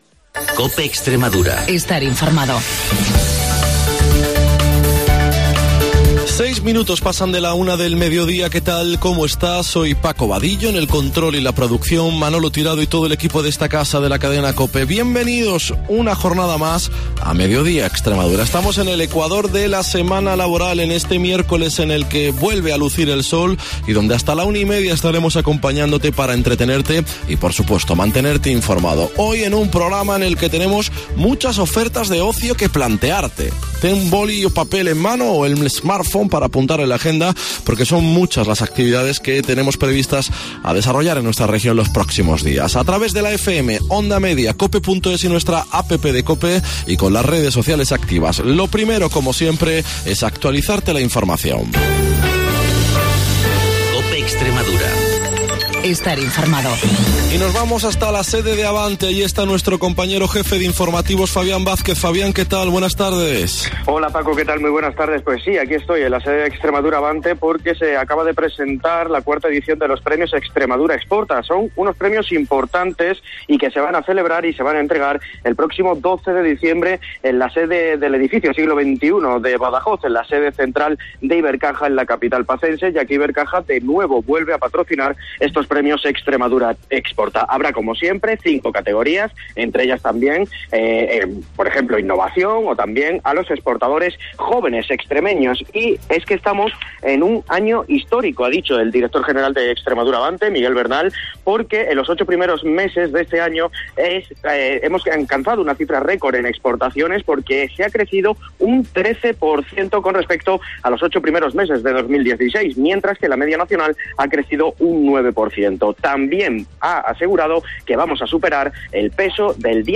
El programa líder de la radio extremeña